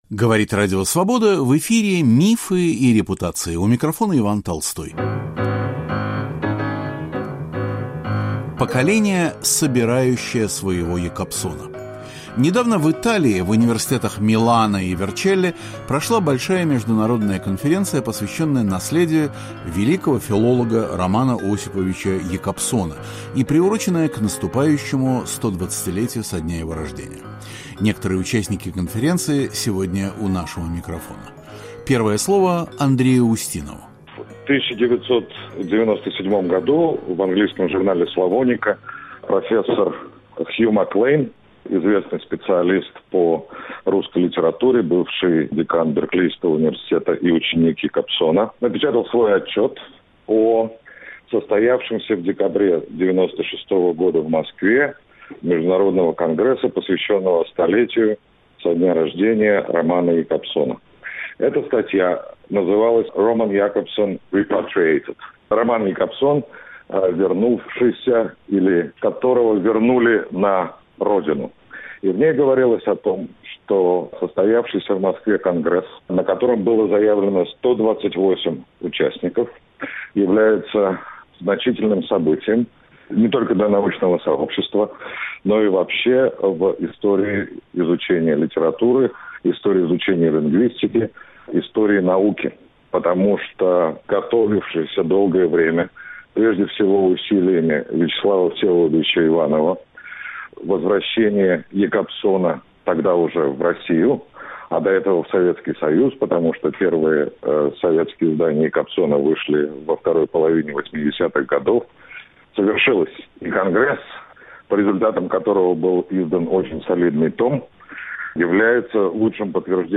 Поколение, собирающее своего Якобсона. Говорят участники конференции